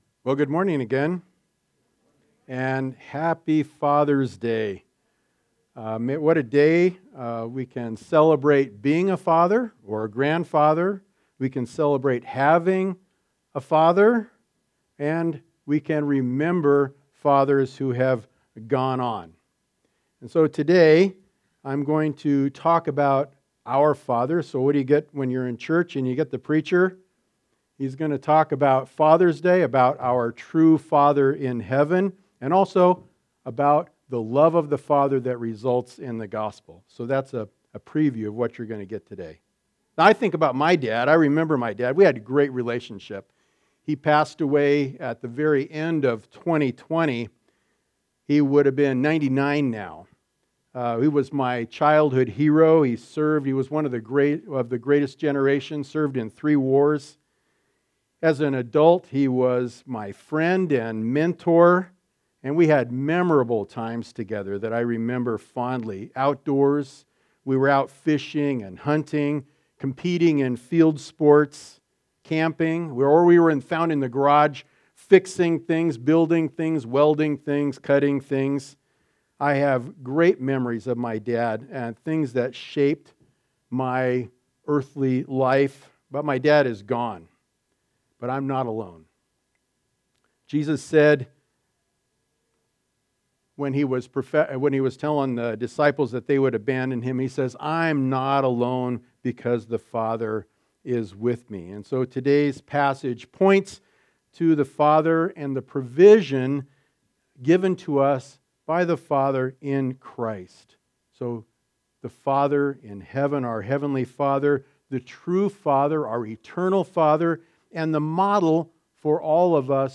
Passage: John 16:25-28 Service Type: Sunday Service